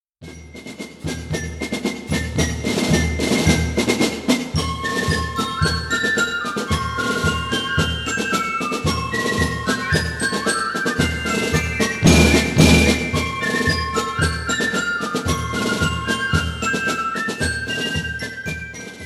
Unser Erwachsenenzug präsentiert 2019 ...
MÄRSCHE